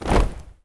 AFROFEET 6-R.wav